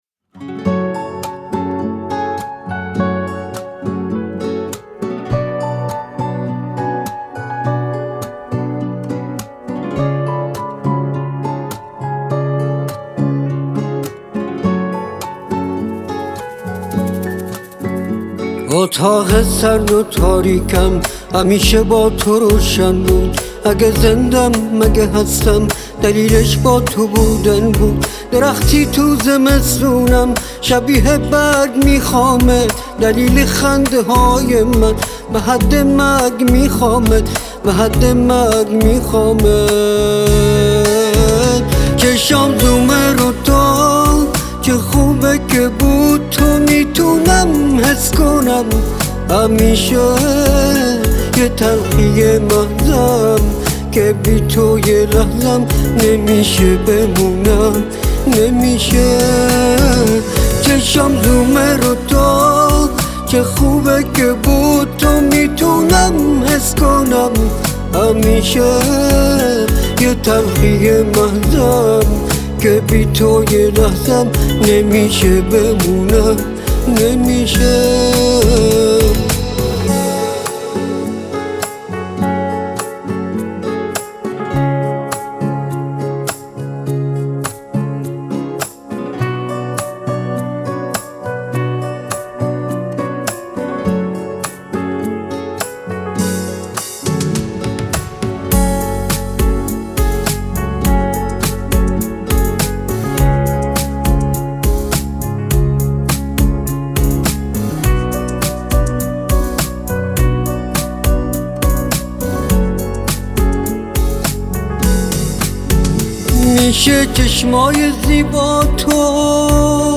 موزیک فارسیموزیک محلی